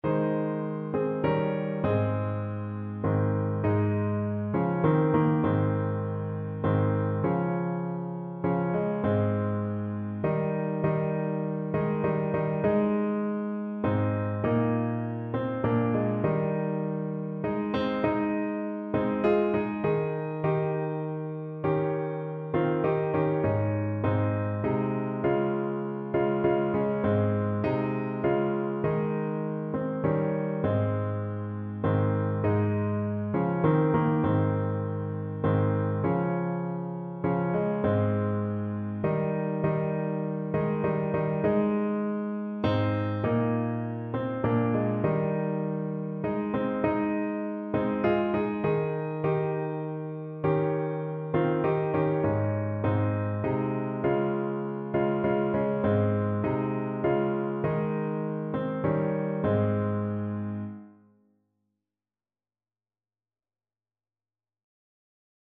Christian
3/4 (View more 3/4 Music)